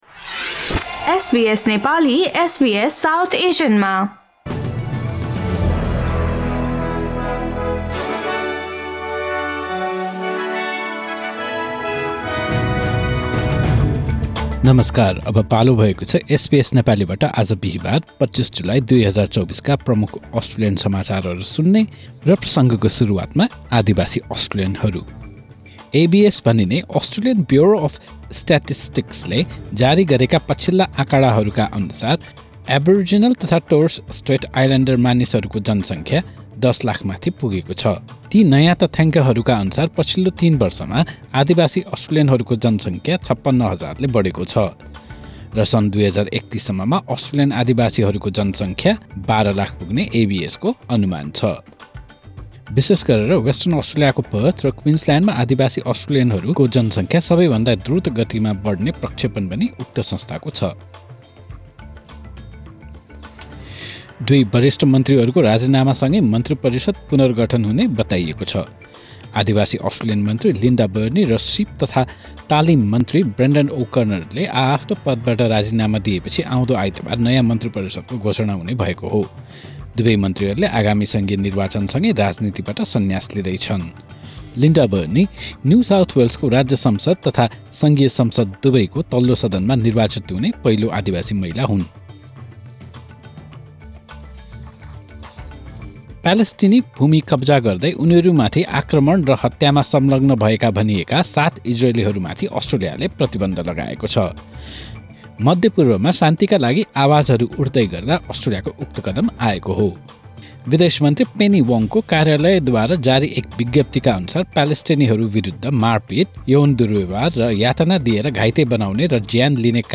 SBS Nepali Australian News Headlines: Thursday, 25 July 2024